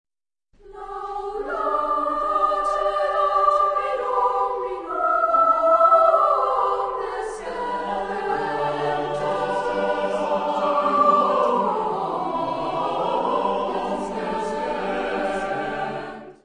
Género/Estilo/Forma: Motete ; Sagrado
Tipo de formación coral: SATB + SATB  (8 voces Doble coro )
Tonalidad : sol mayor
Ref. discográfica: 4.Deutscher Chorwettbewerb, 1994